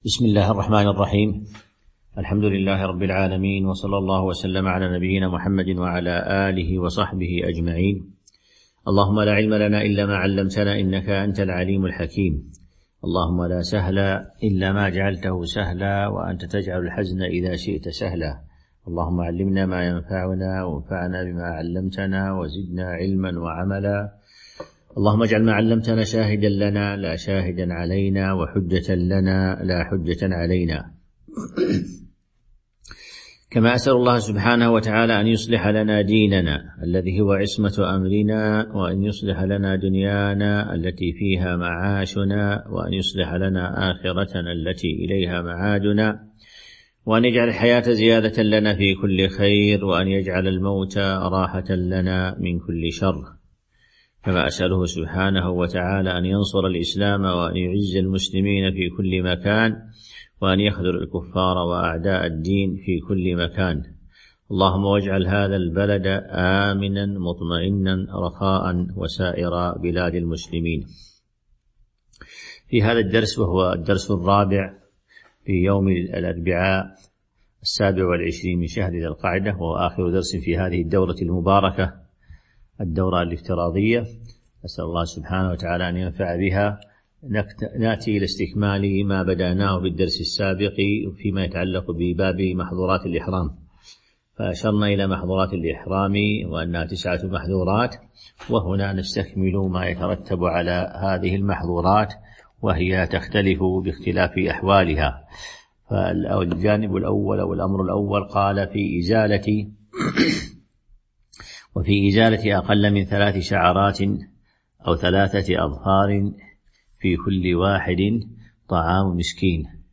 تاريخ النشر ٢٧ ذو القعدة ١٤٤٢ هـ المكان: المسجد النبوي الشيخ